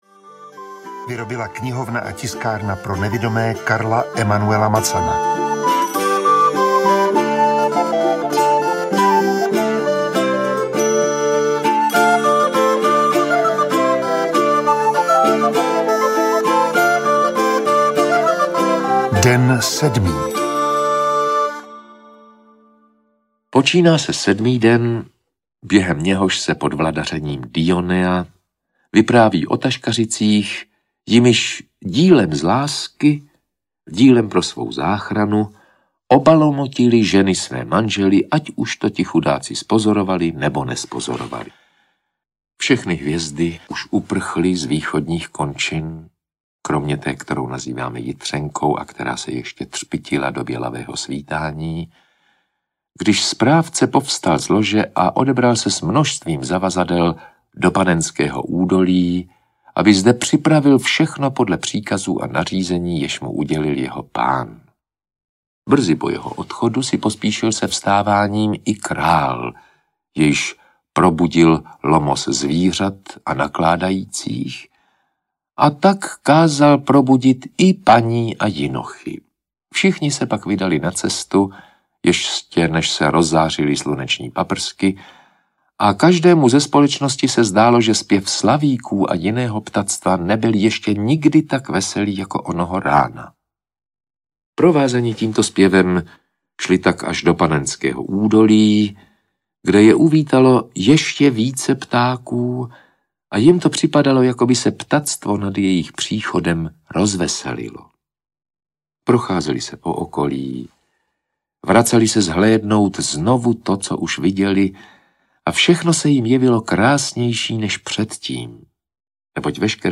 Dekameron - Den sedmý audiokniha
Ukázka z knihy
• InterpretRudolf Pellar